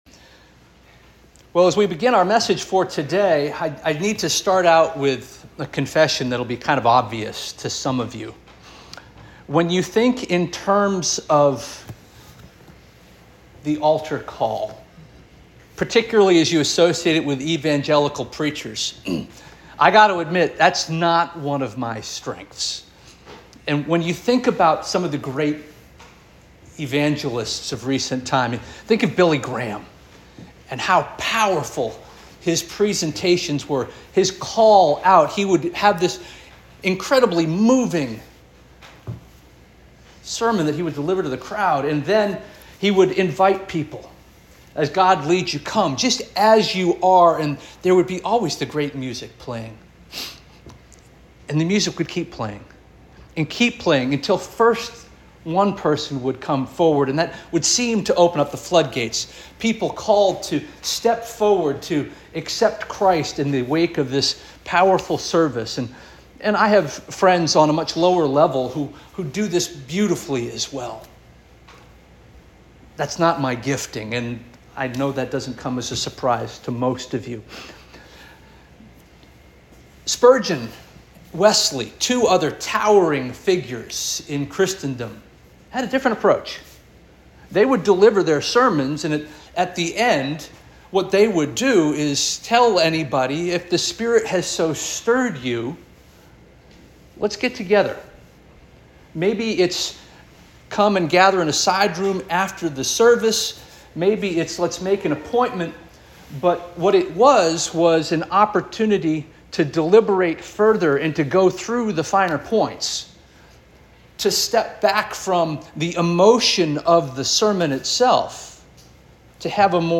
April 27 2025 Sermon - First Union African Baptist Church